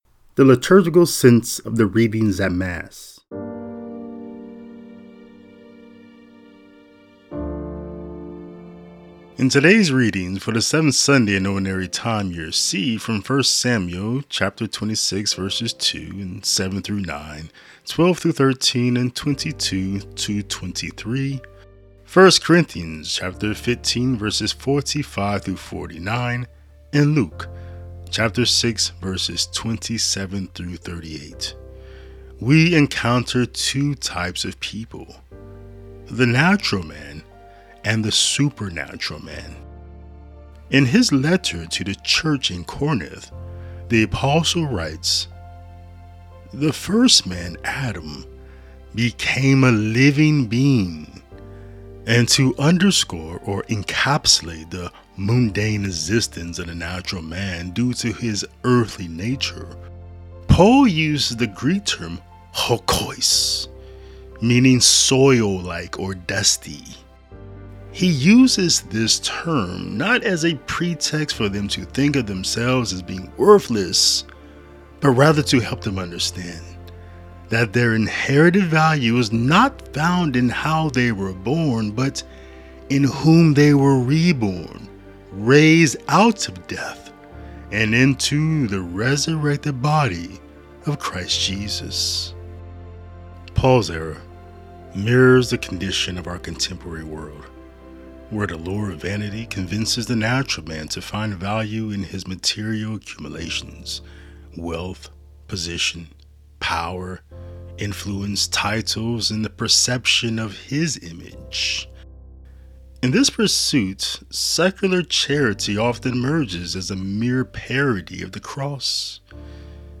A Commentary and Reflection on the Readings for the Seventh Sunday in Ordinary Time.